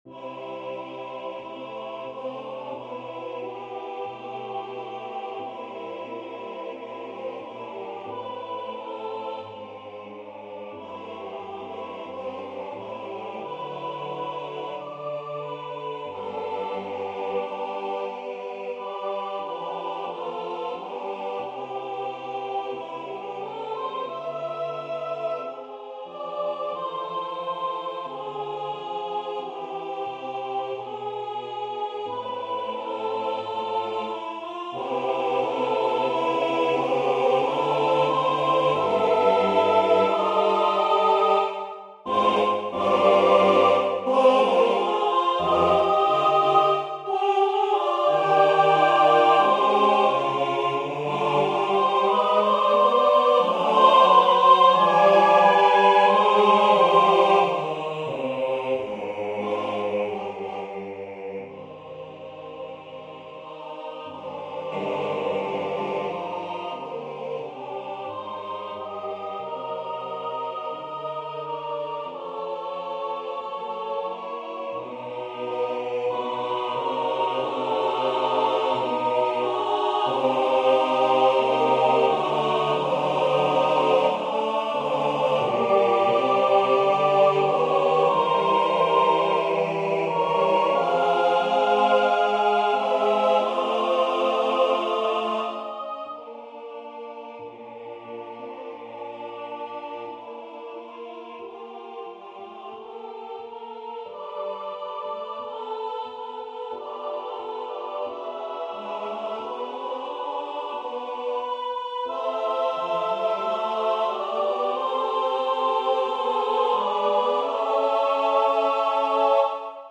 Number of voices: 4vv Voicing: SATB Genre: Secular, Art song
Language: English Instruments: A cappella